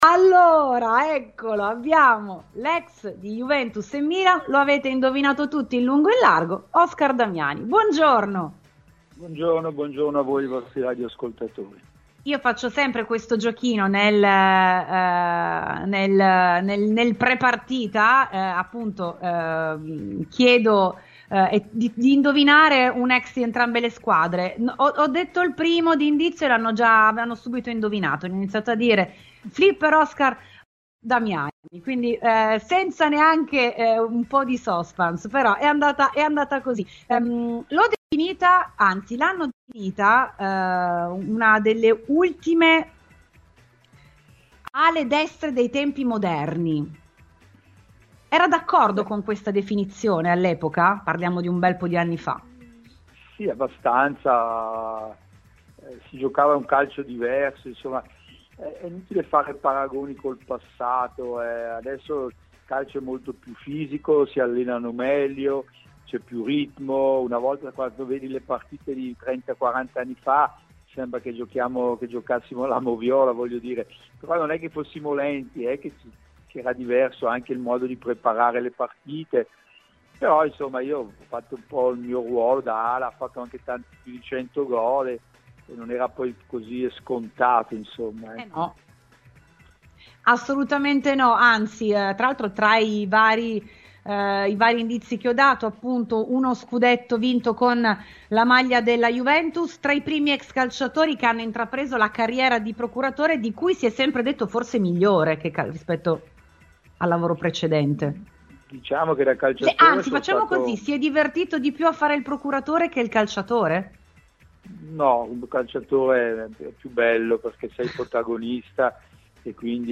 Ha parlato, anche di lui e di come si immagina Juventus-Milan, un doppio ex della partita ai microfoni di RBN Cafè su Radiobianconera, Oscar Damiani.